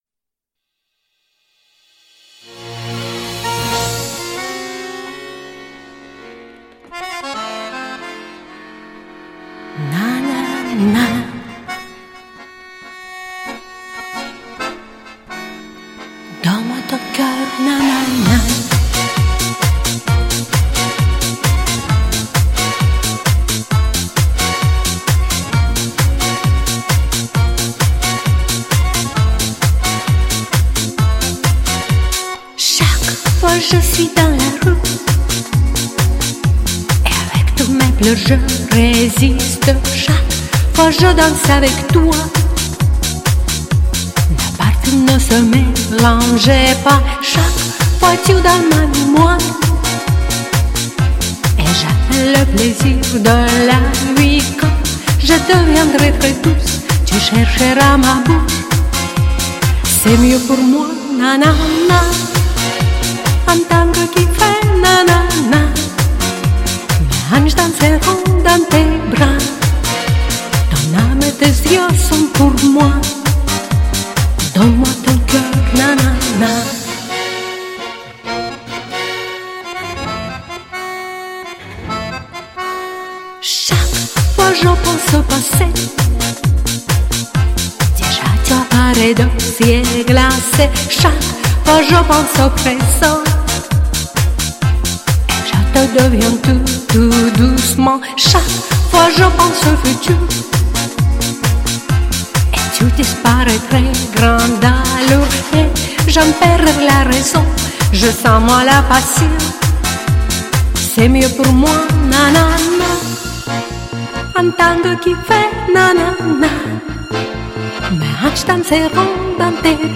Ваш голос ,немного, тонет в минусе,теряется там ....